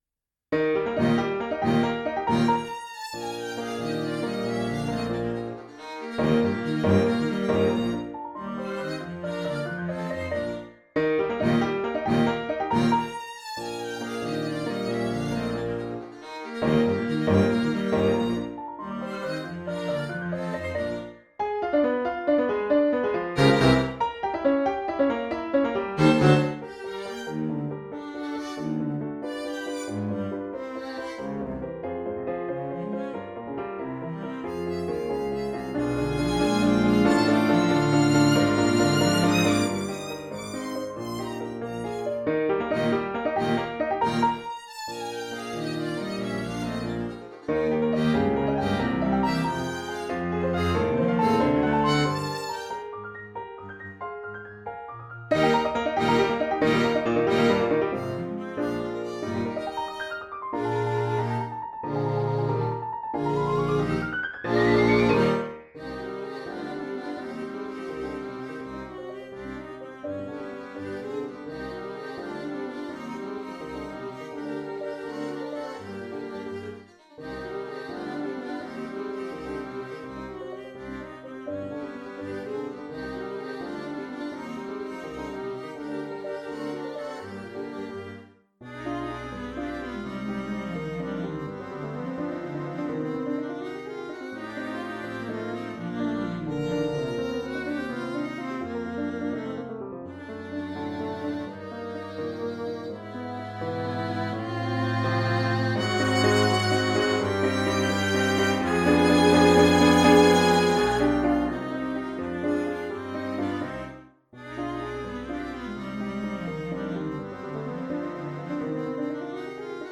Soundbite 2nd Movt
have had to resort to midi files
For Violin, Viola, Cello, Bass and Piano